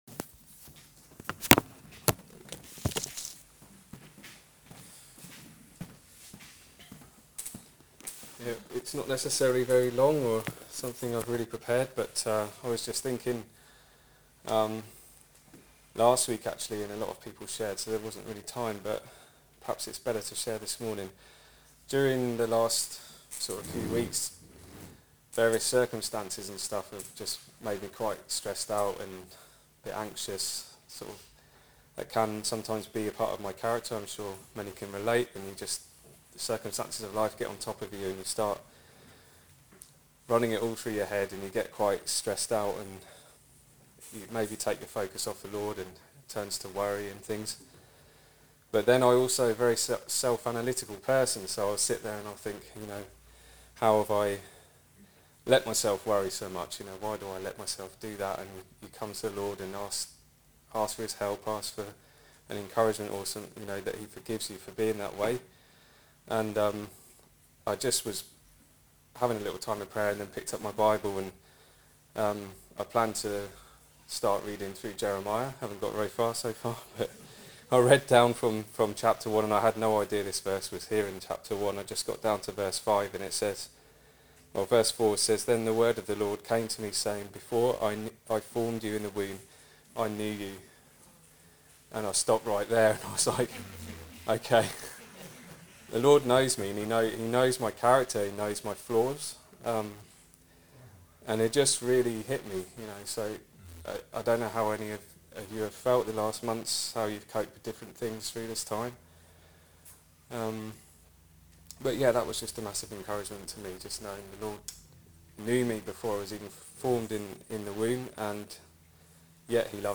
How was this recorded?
This morning’s service was our second service following the lockdown and we were very blessed to be able to meet together to share, to worship the Lord and hear His Word ministered.